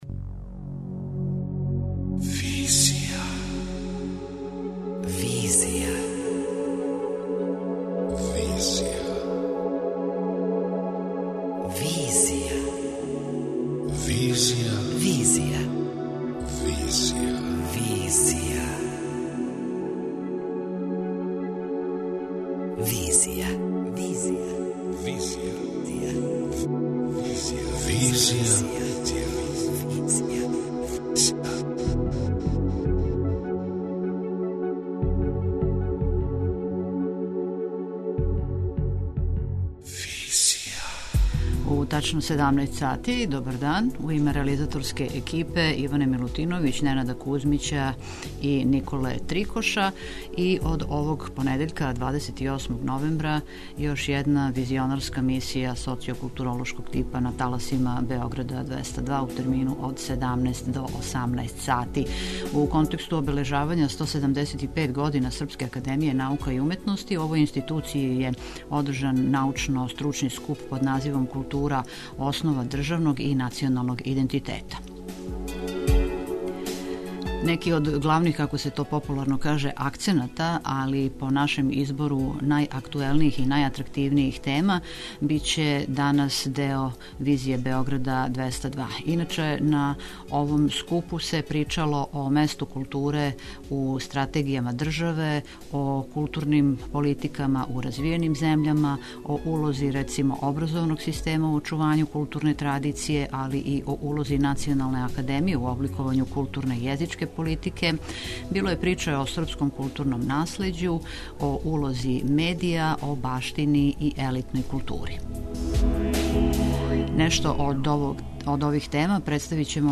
Српска академија наука и уметности организовала је, у оквиру циклуса ''Стратешки правци развоја Србије у 21. веку'', научно-стручни скуп ''Култура - основа државног и националног идентитета''.